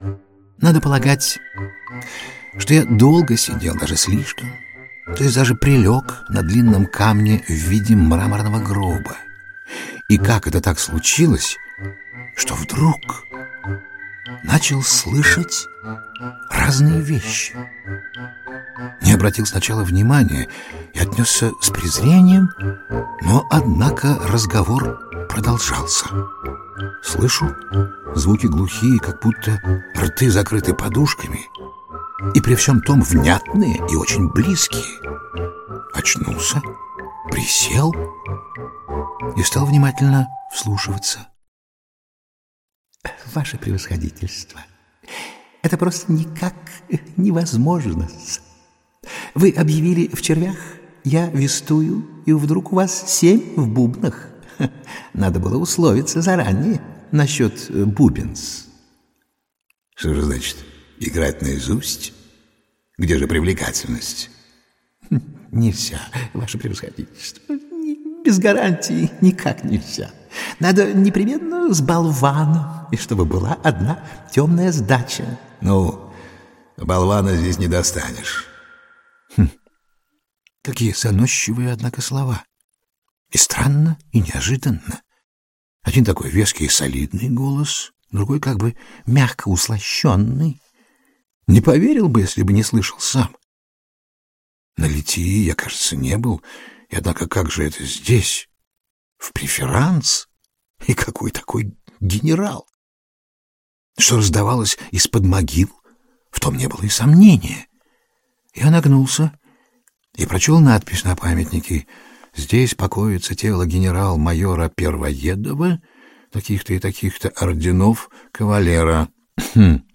Предлагаемая вашему вниманию аудиокнига раскрывает Владимира Еремина в новом качестве – как изумительного чтеца. Итак, Еремин читает Достоевского… 1.